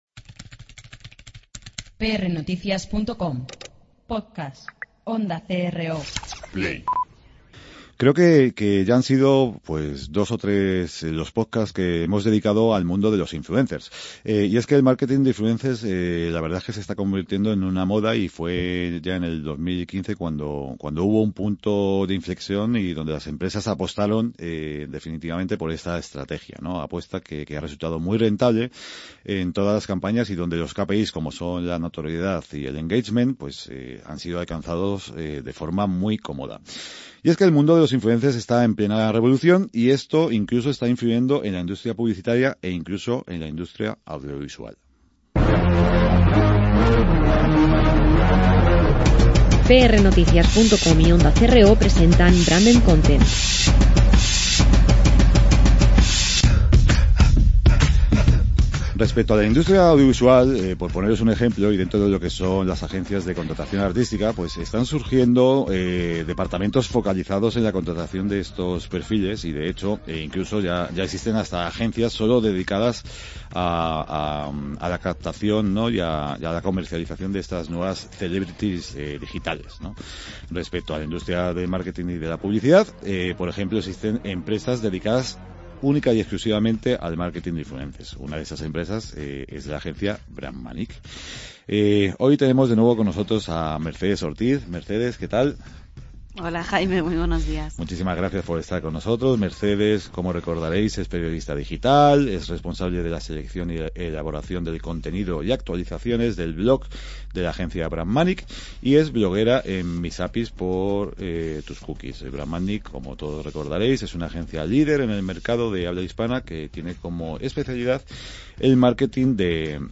Hoy entrevistamos